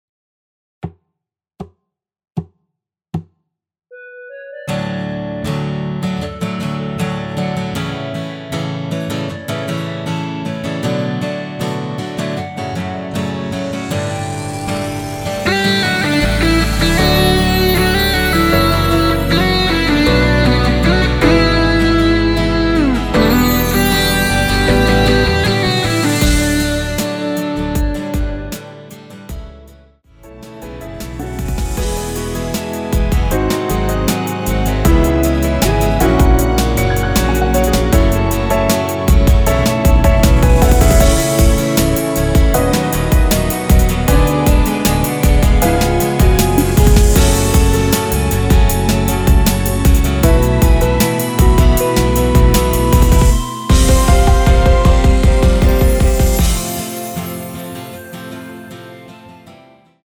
전주 없이 시작하는 곡이라서 노래하기 편하게 카운트 4박 넣었습니다.(미리듣기 확인)
원키에서(-2)내린 멜로디 포함된 MR입니다.(미리듣기 확인)
노래방에서 노래를 부르실때 노래 부분에 가이드 멜로디가 따라 나와서
앞부분30초, 뒷부분30초씩 편집해서 올려 드리고 있습니다.